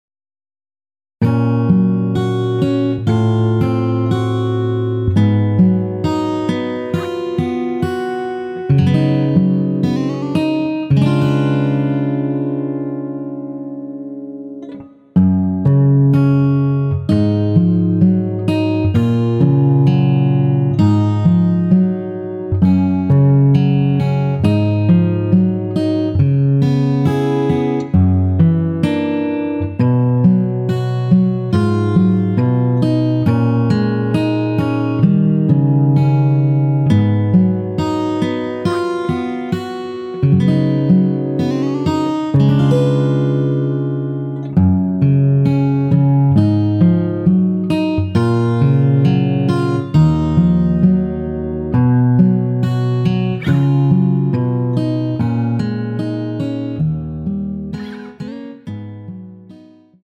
원키에서(-1)내린 MR입니다.
F#
앞부분30초, 뒷부분30초씩 편집해서 올려 드리고 있습니다.
중간에 음이 끈어지고 다시 나오는 이유는